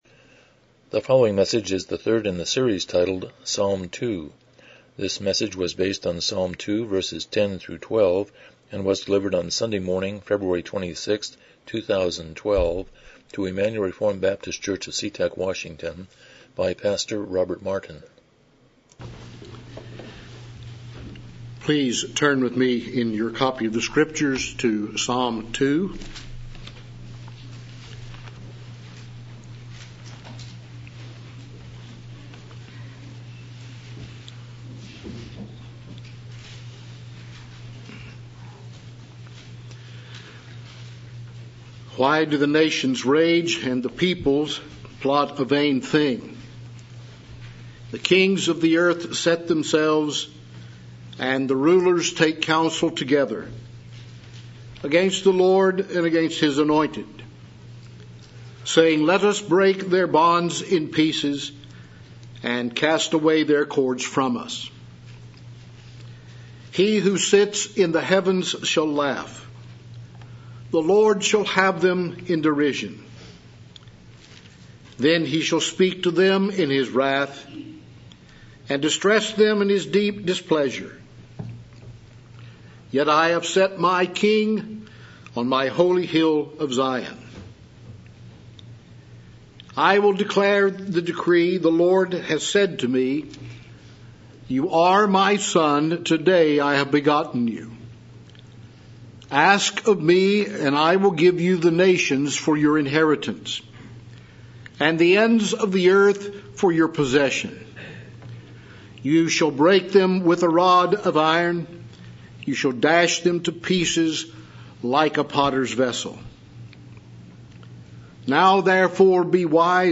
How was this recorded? Psalm 2:10-12 Service Type: Morning Worship « 140 Chapter 29.1